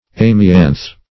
amianth - definition of amianth - synonyms, pronunciation, spelling from Free Dictionary Search Result for " amianth" : The Collaborative International Dictionary of English v.0.48: Amianth \Am"i*anth\, n. See Amianthus .
amianth.mp3